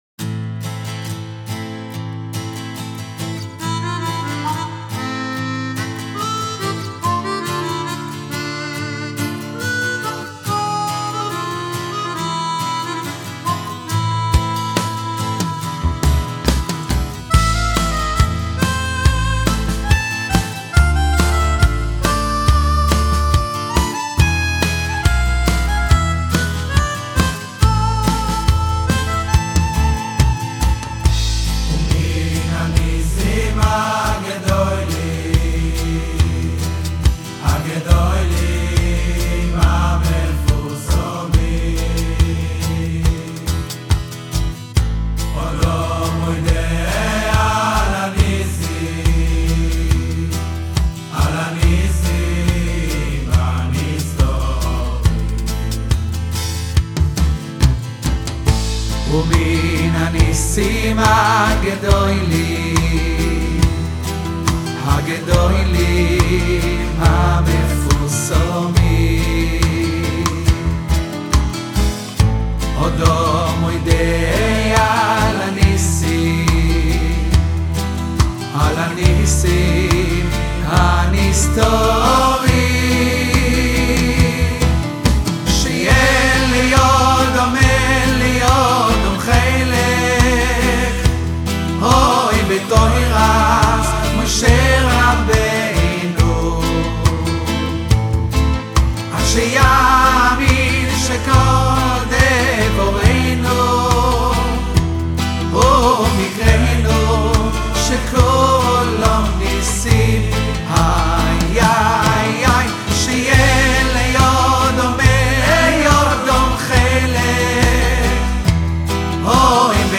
שיר קומזיץ